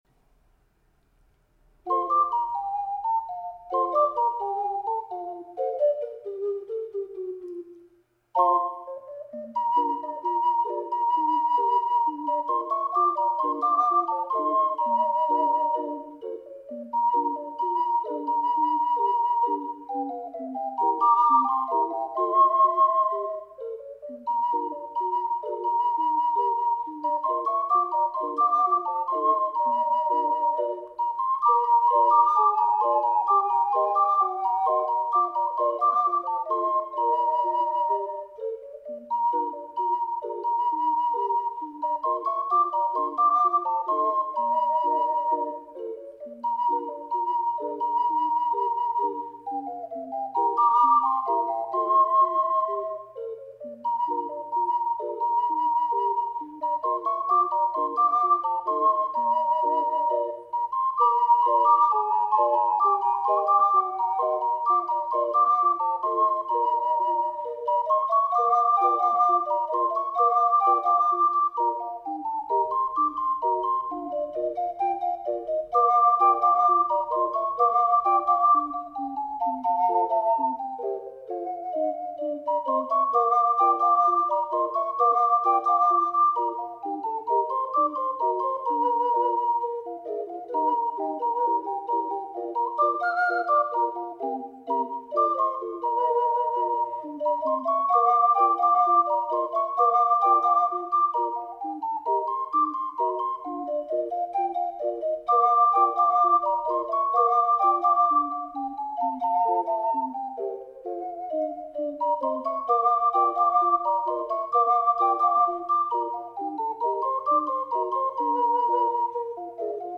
試奏五重奏